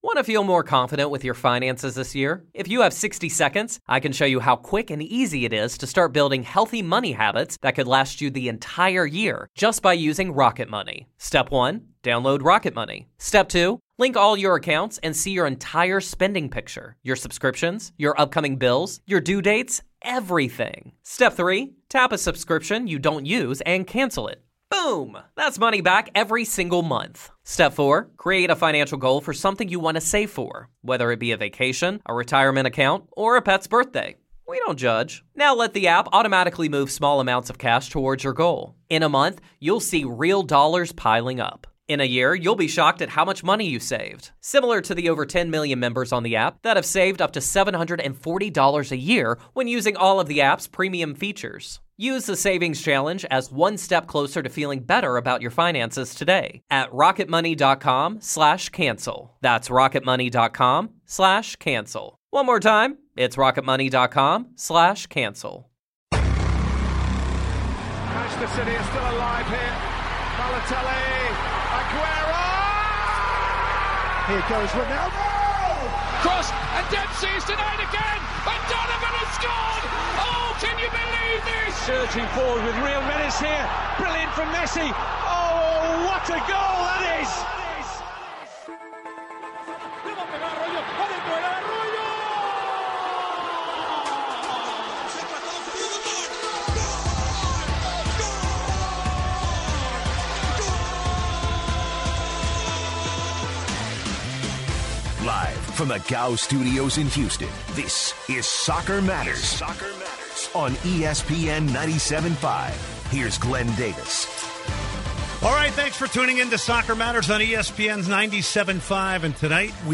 takes calls from listeners